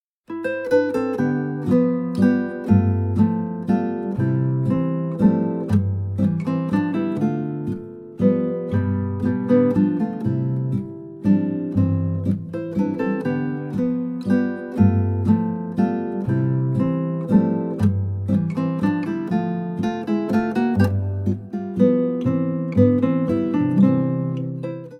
12 Gitarren - Stückl